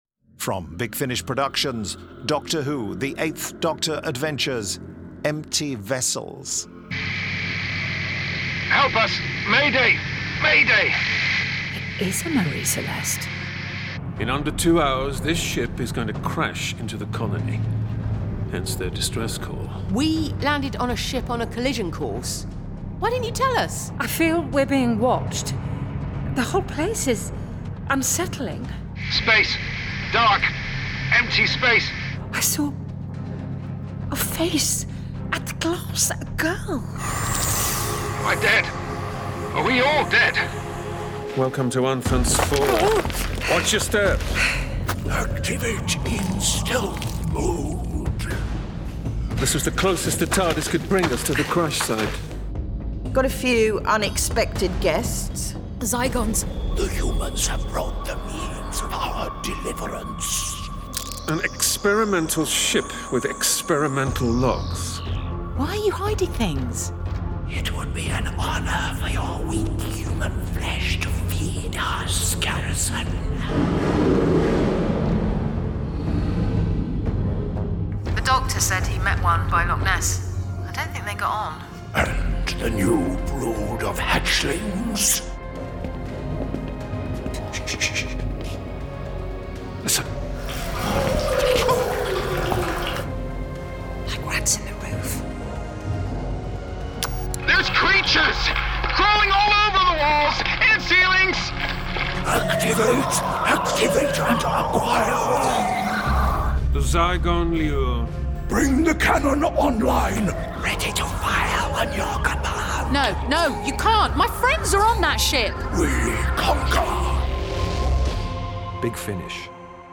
Award-winning, full-cast original audio dramas from the worlds of Doctor Who
Starring Paul McGann Nicola Walker